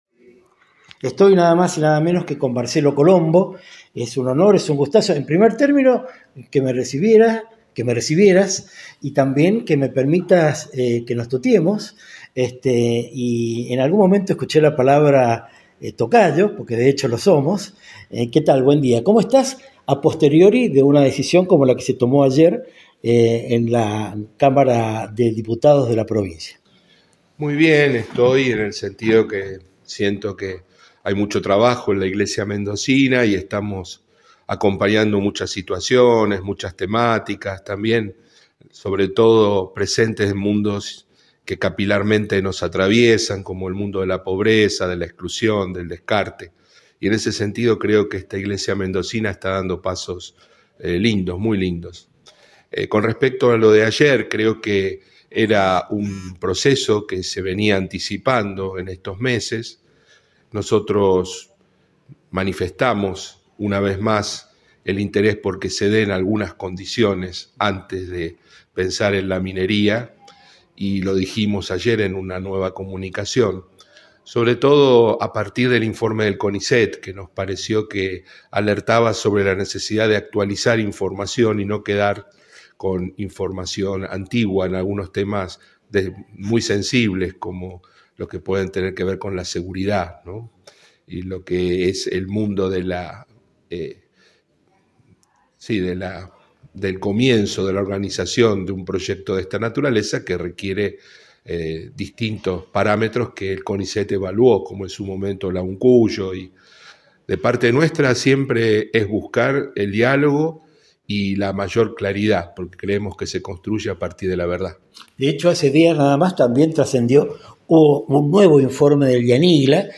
De ahí en adelante fructificó una tenida sin más acuerdo previo que el de realizar la entrevista.
En el caso de nuestro entrevistado, también lo social se halla en el centro de sus preocupaciones y sin subir el tono de su voz, describe todo lo que se hace junto a los argentinos y argentinas que menos tienen y más necesitan.